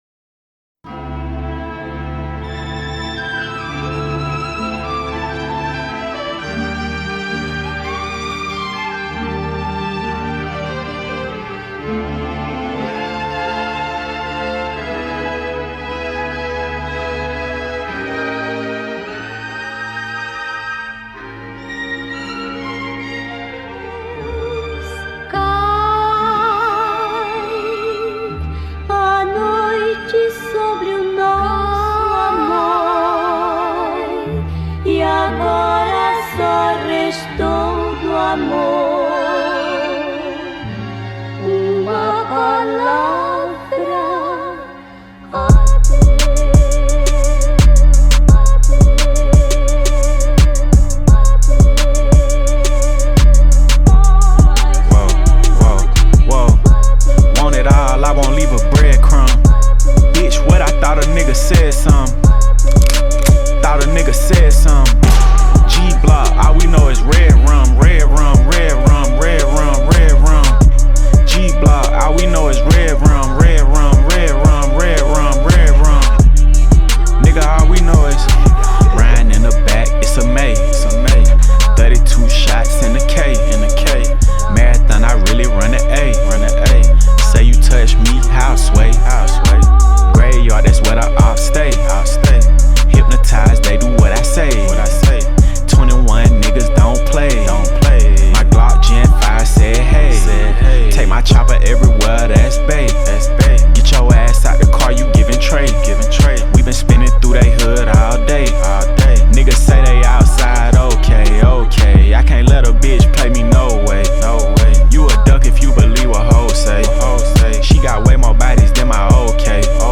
2024-03-24 19:13:58 Gênero: Trap Views